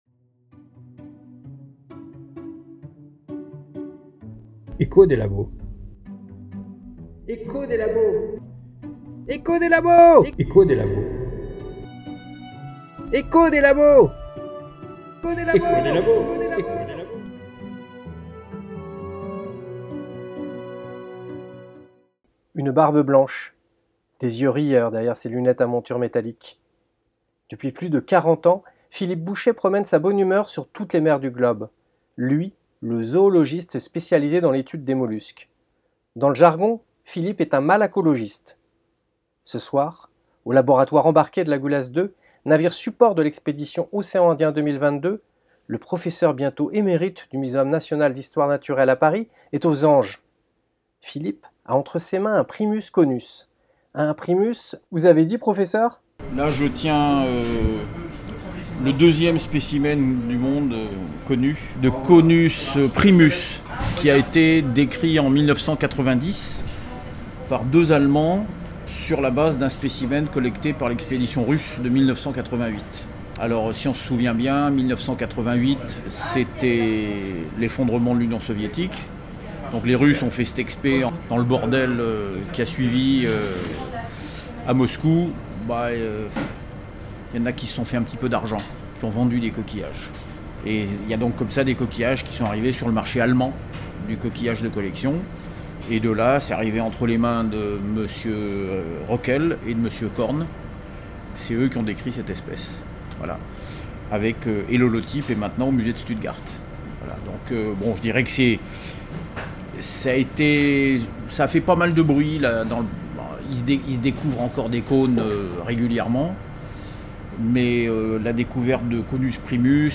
Sábado 12 de noviembre de 2022, Banco Maya Saya en el Océano Índico.